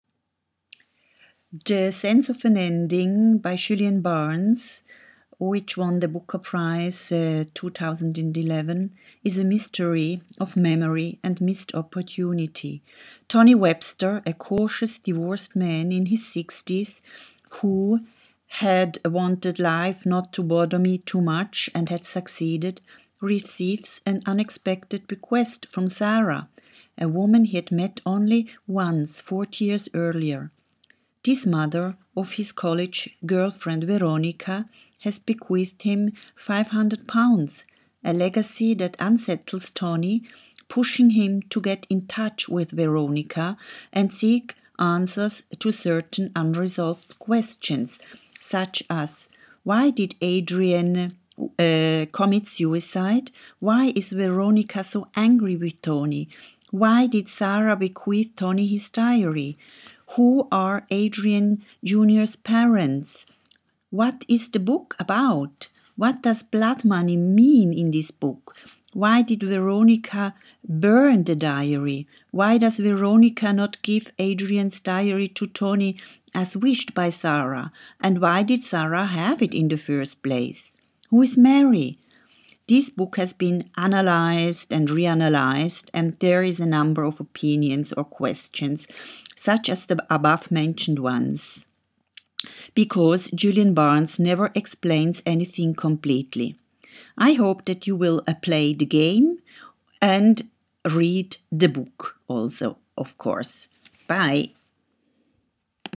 Short summary to which you can just listen to with questions.